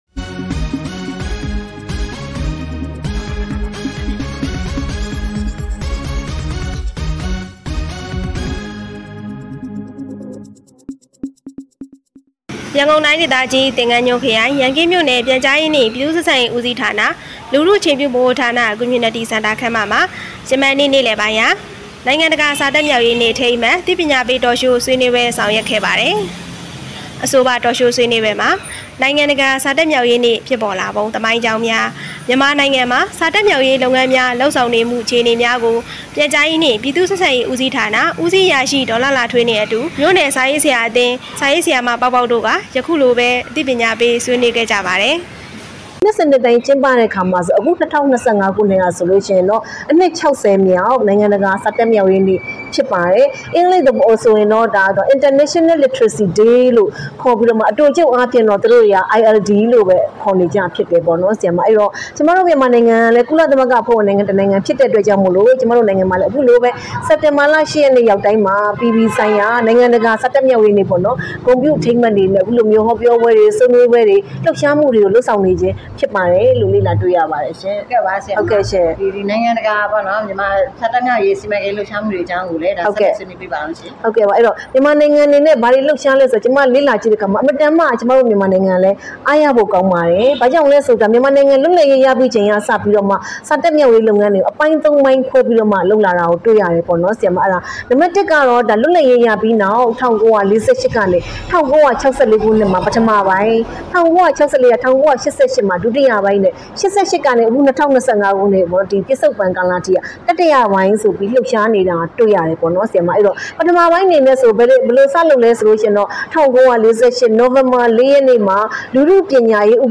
ရန်ကင်းမြို့နယ်၌ နိုင်ငံတကာစာတတ်မြောက်ရေးနေ့အထိမ်းအမှတ် Talk Show ပြုလ...